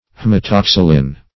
Meaning of haematoxylin. haematoxylin synonyms, pronunciation, spelling and more from Free Dictionary.
Search Result for " haematoxylin" : The Collaborative International Dictionary of English v.0.48: Haematoxylin \H[ae]m`a*tox"y*lin\ (-t[o^]ks"[i^]*l[i^]n), n. [See H[ae]matoxylon .]